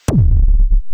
shooting.ogg